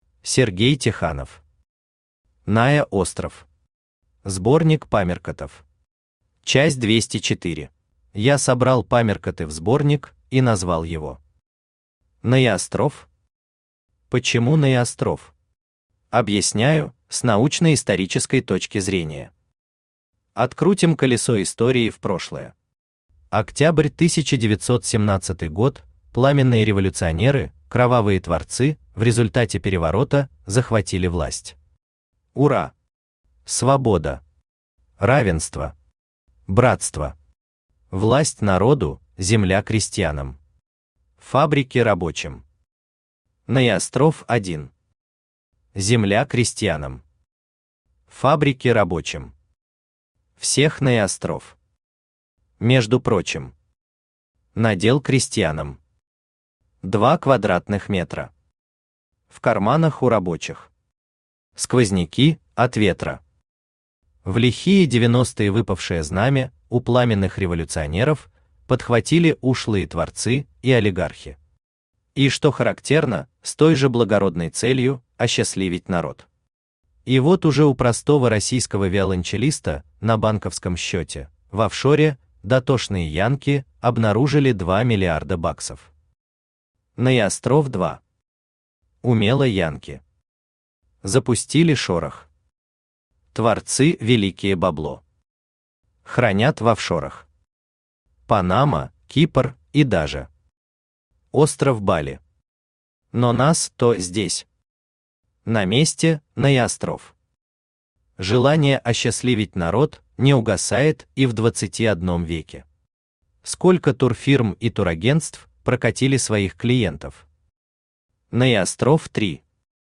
Аудиокнига НаеОстров. Сборник памяркотов. Часть 204 | Библиотека аудиокниг